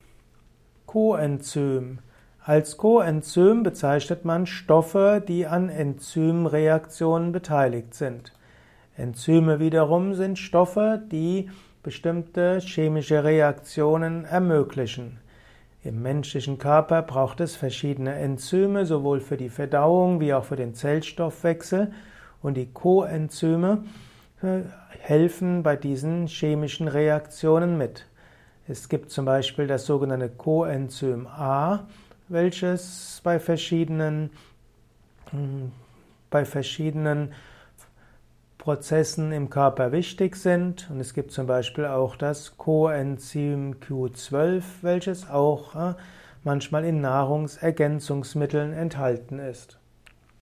Erfahre mehr über den Begriff Coenzym diesem Kurzvortrag